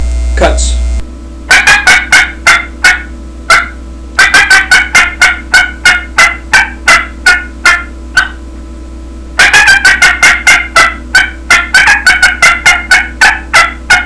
Listen to 14 seconds of cutts
• Makes medium-toned and raspy yelps, cackles, clucks, and cutts at all volume levels.
qbgroldbosshencutts14.wav